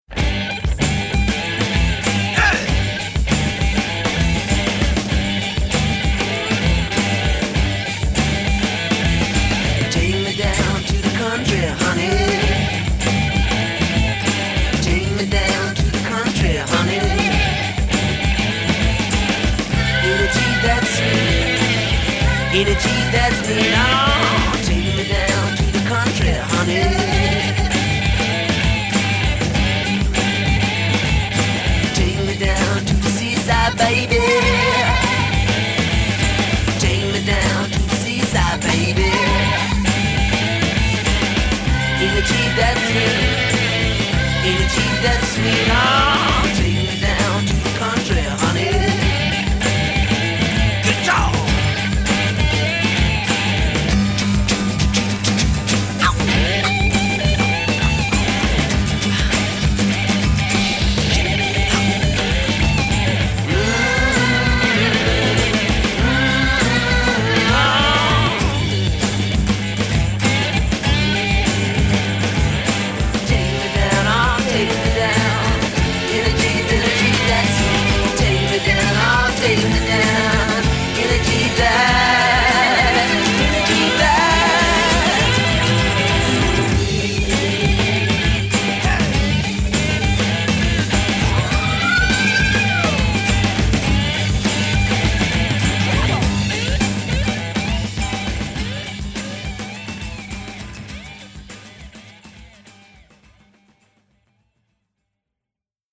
Глэм рок Glam rock Рок